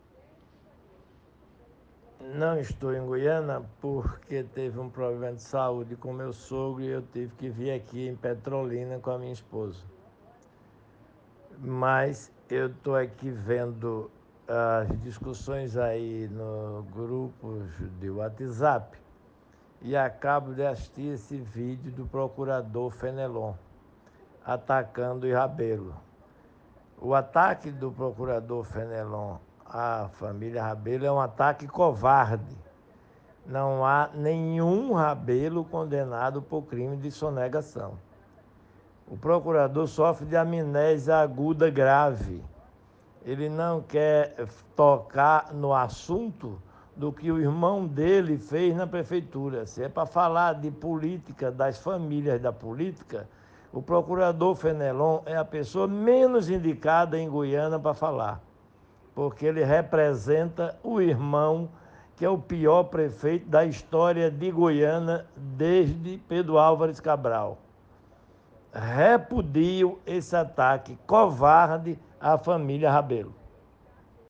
Fala de Fernando Veloso.